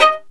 Index of /90_sSampleCDs/Roland - String Master Series/STR_Violin 1-3vb/STR_Vln3 % + dyn
STR VLN JE13.wav